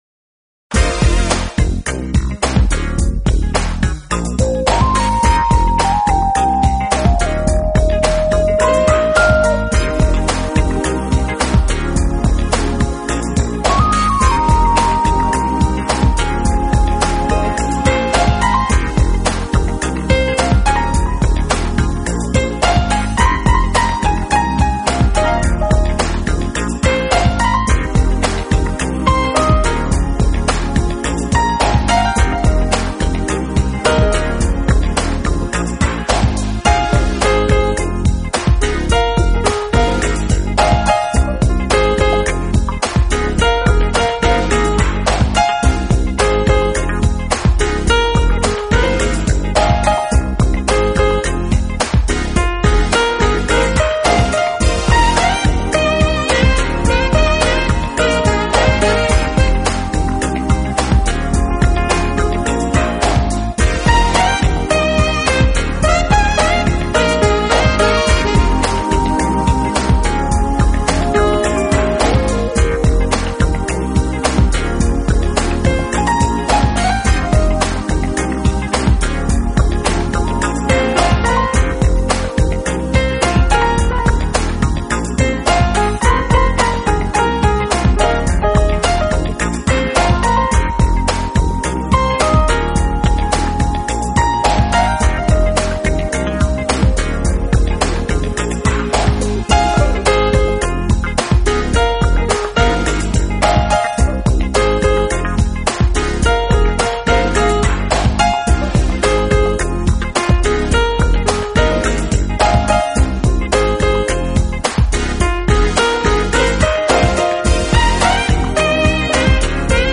所走的是美国主流器乐演奏路线，当然也是非常主流化的Smooth Jazz。
他们的音乐从爵士精神出发，强调黑人音乐里“节奏蓝调”（R&B）元素，
柔情似水的演出与略带Funk的曲风，让他们在乐界赢得不错的口碑，证明了当时